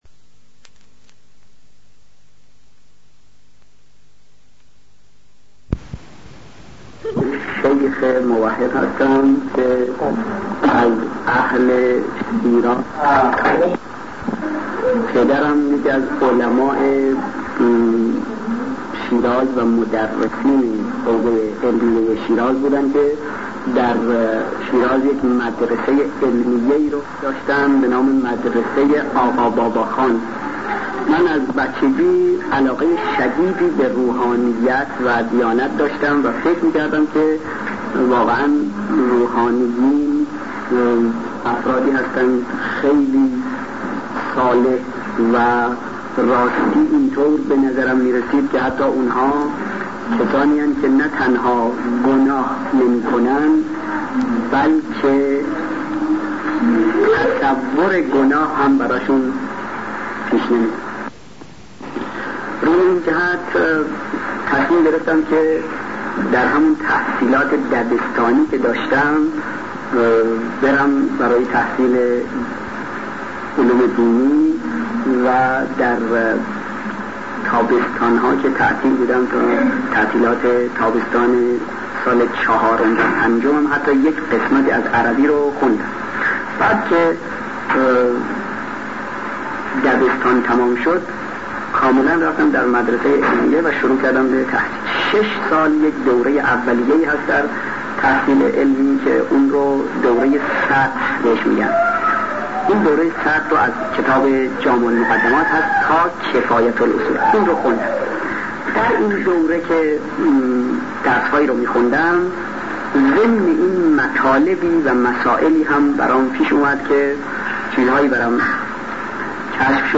به زبان خودشان